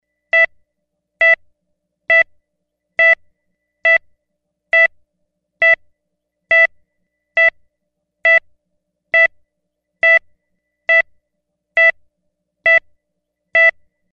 На этой странице собраны звуки кардиомониторов — от ровного ритма здорового сердца до тревожных сигналов критических состояний.
Звук пульса для видеомонтажа